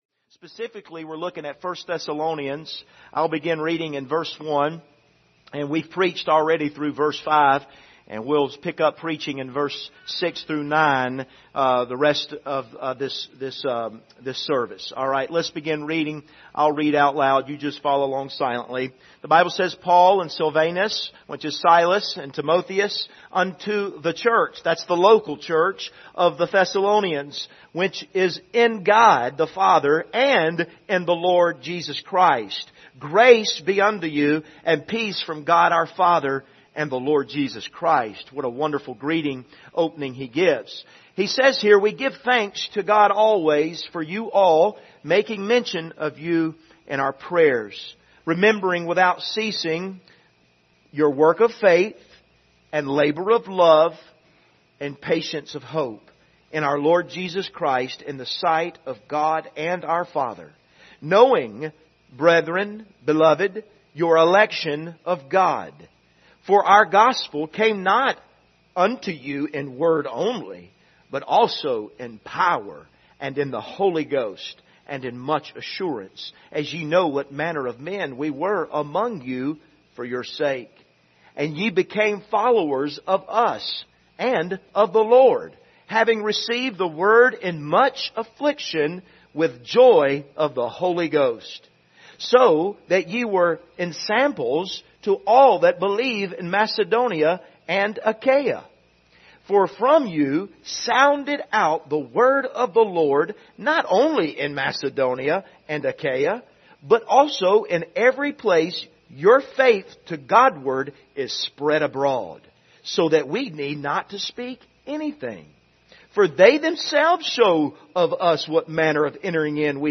Passage: 1 Thessalonians 1:6-9 Service Type: Sunday Morning